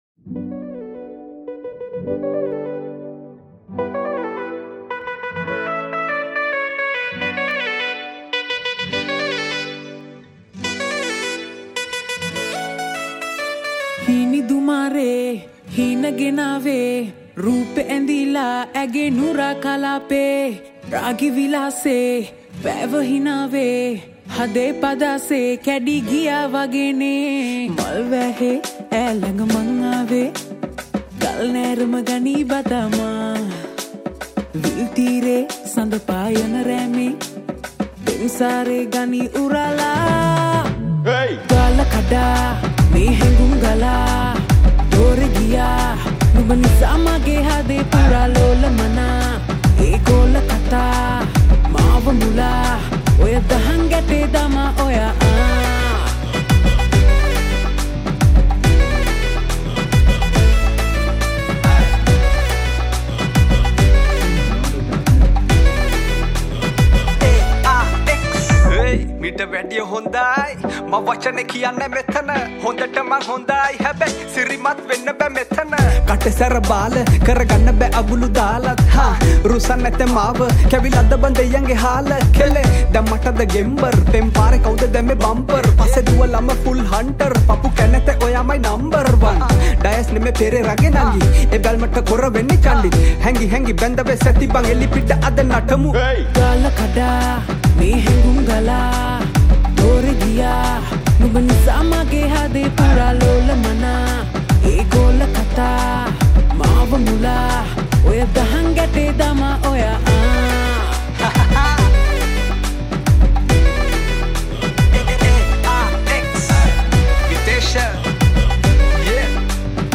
Guitars
Backup Vocals